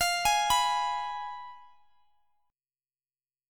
Fdim Chord
Listen to Fdim strummed